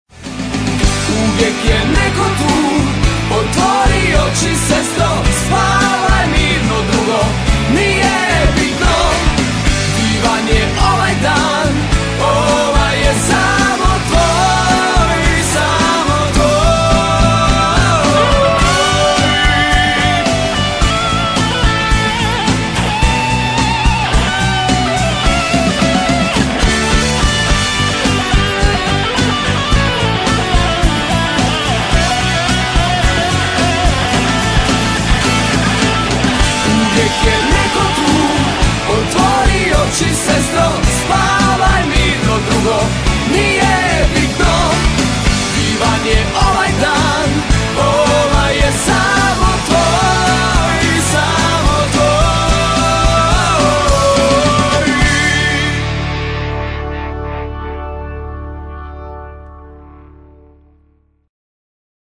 vokal pop, gitarski break